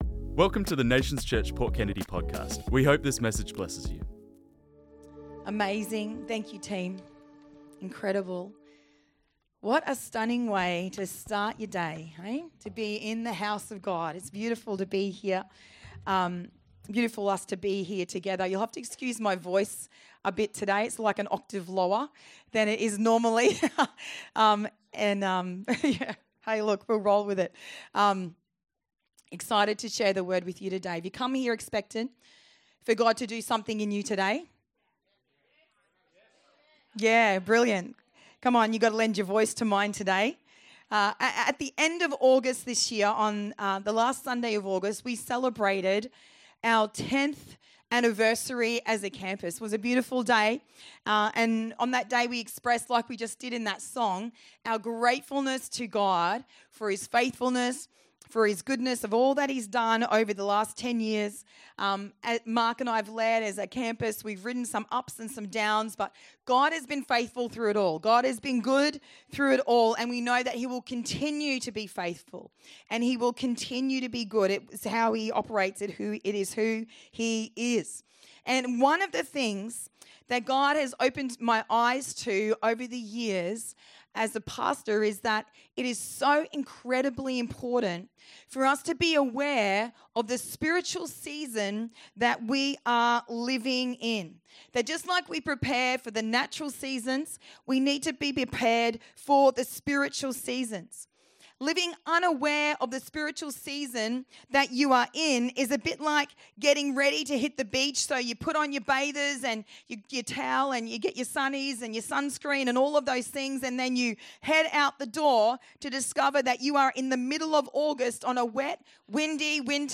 This message was preached on Sunday 5th October 2025